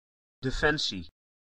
Ääntäminen
IPA: [de.fɛn.siː]